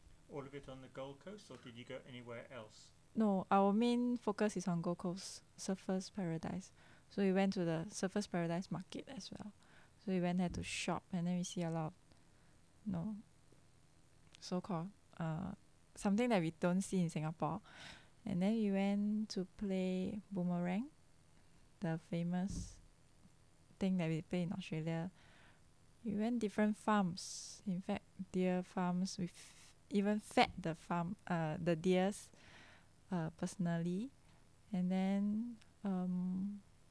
The NIE Corpus of Spoken Singapore English
Female Speaker 23